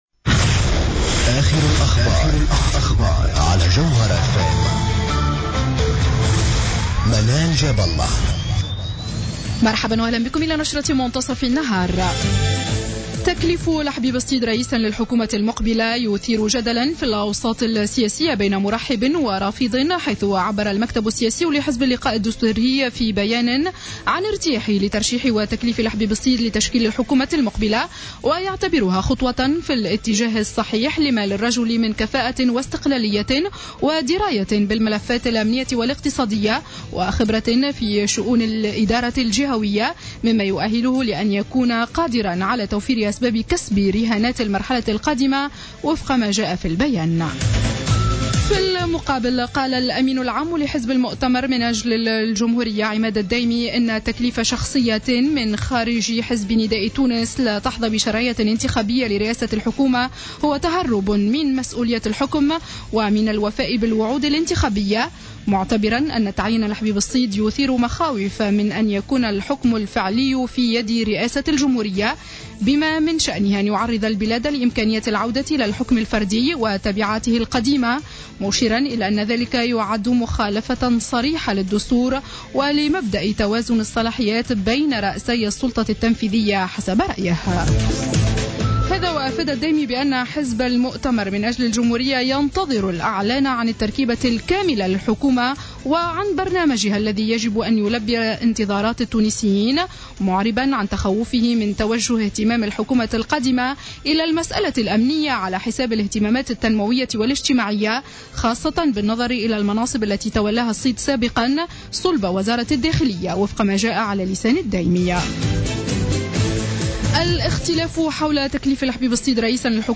نشرة أخبار منتصف النهار ليوم الثلاثاء 06-01-15